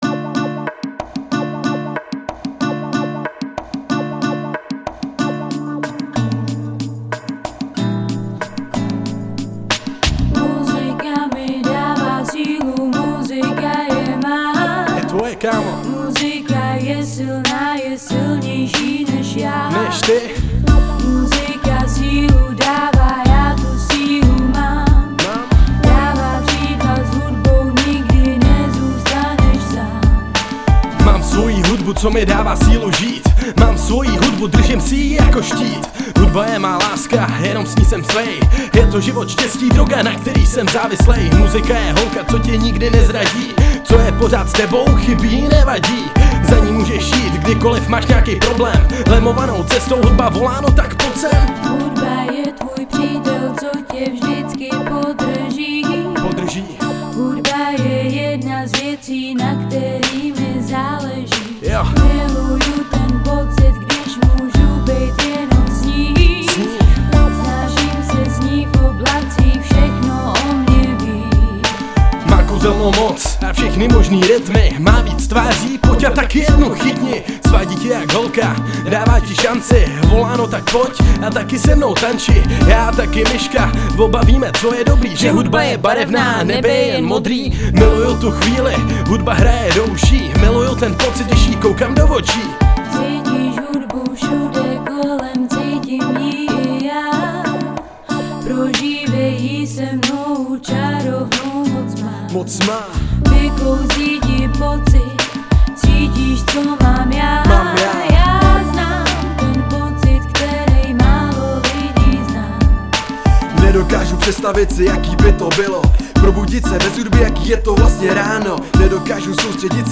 3 Styl: Hip-Hop Rok